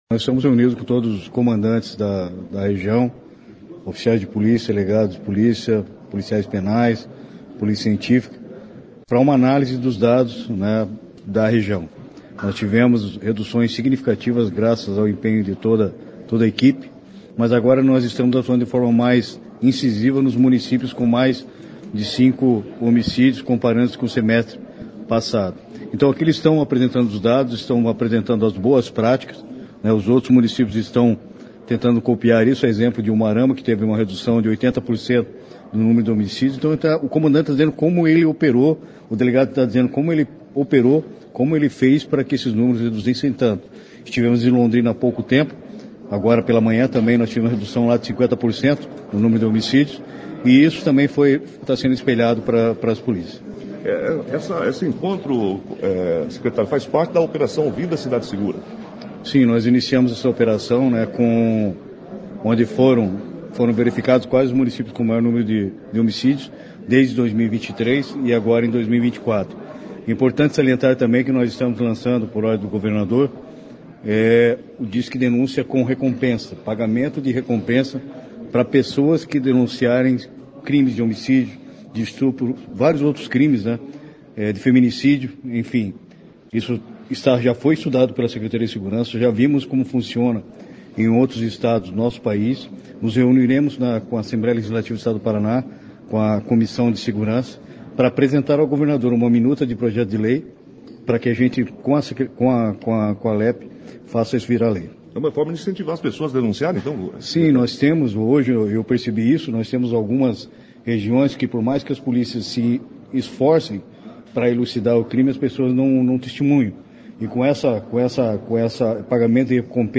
Ouça a entrevista coletiva concedida pelo secretário Hudson Leôncio Teixeira.